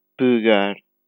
Portuguese European[20] pegar
[pɯ̞ˈɣäɾ] 'to grab' Reduced vowel. Near-close.[20] Typically transcribed in IPA with ⟨ɨ⟩. See Portuguese phonology
Pegar_european_pt.wav.mp3